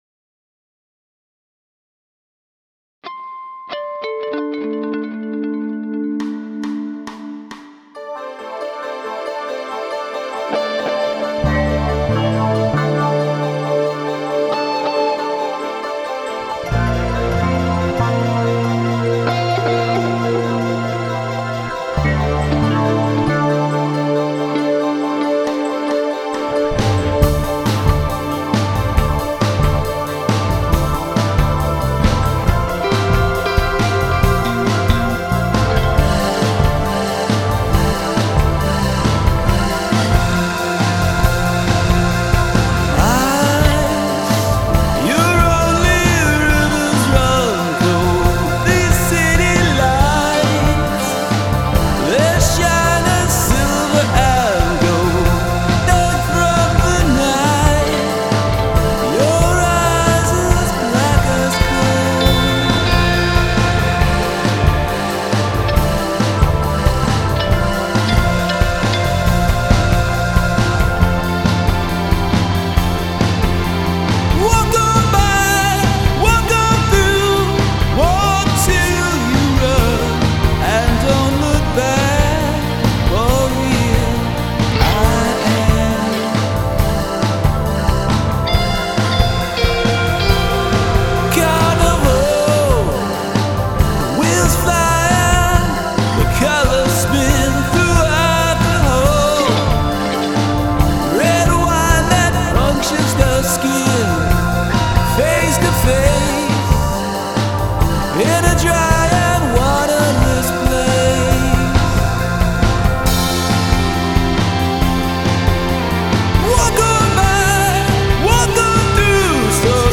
Multi-track recording
vocals, bass, drum application
Keyboards
Guitar